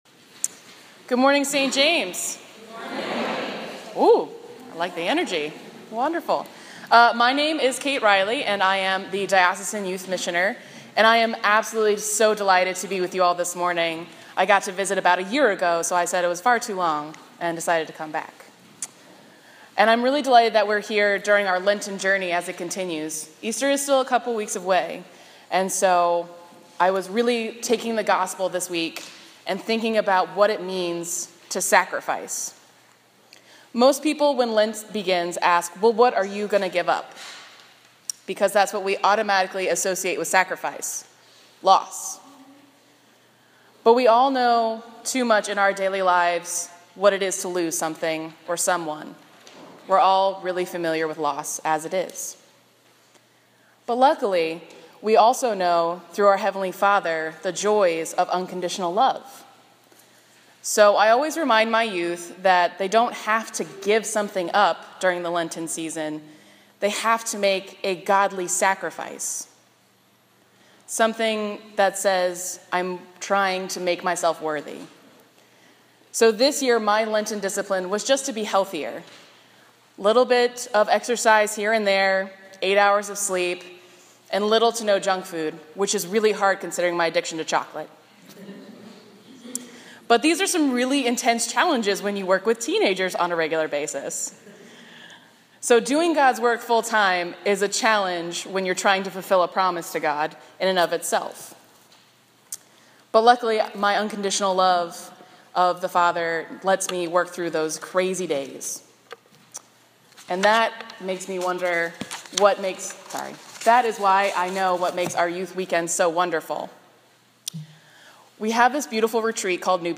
A Sermon for the Fifth Sunday of Lent 2016